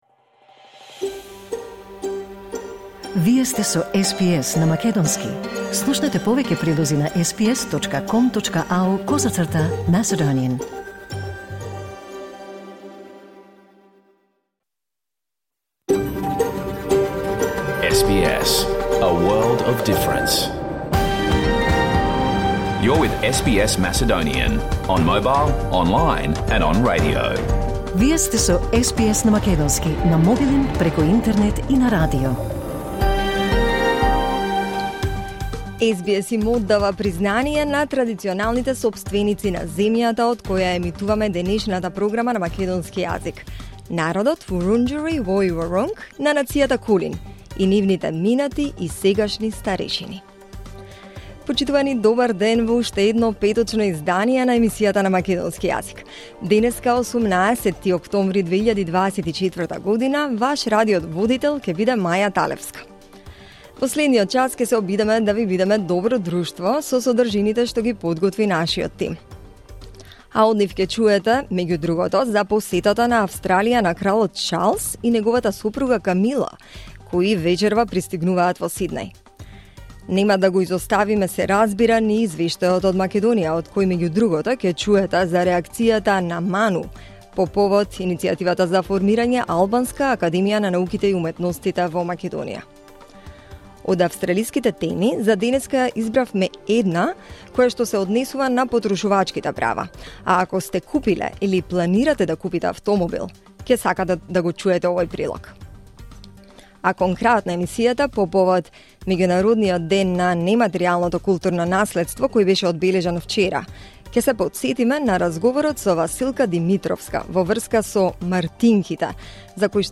SBS Macedonian Program Live on Air 18 October 2024